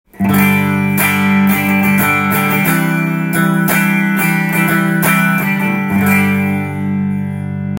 更にトーンノブを絞って弾いたみところ。。
トーンを下げても音の質が変わらずトーンノブを回しながら